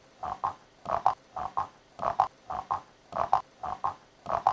le crapaud
Ecoute_Crapaud.mp3